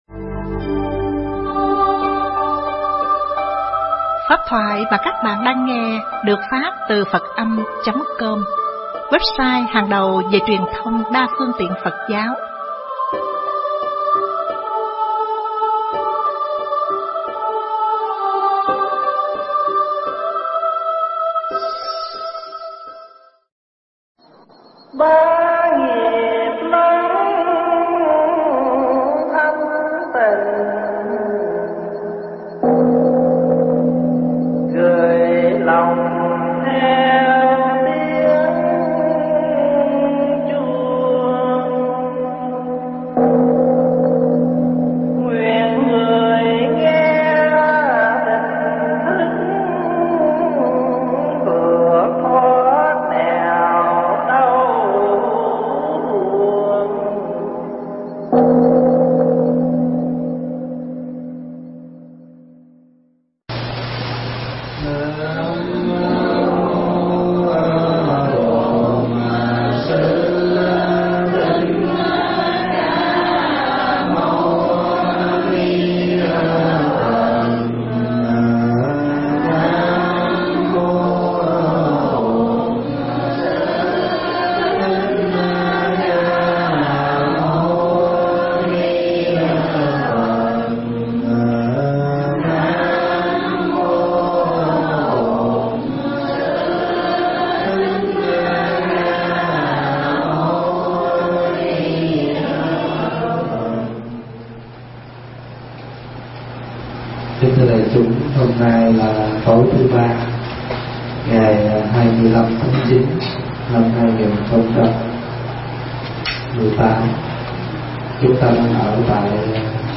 giảng tại tư gia